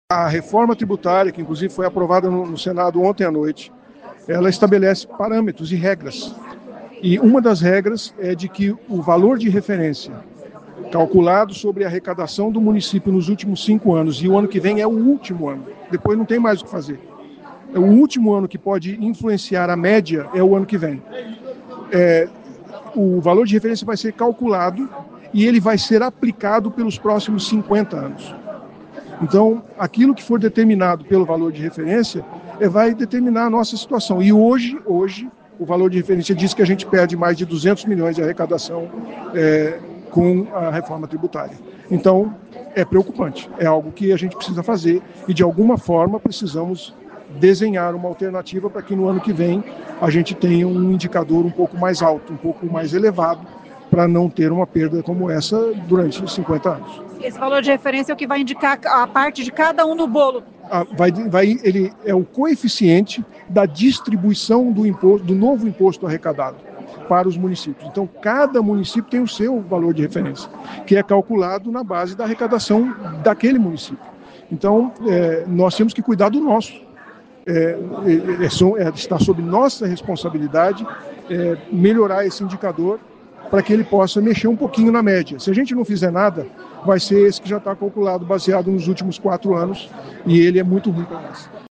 E esse indicador será considerado pelos próximos 50 anos, explica o prefeito: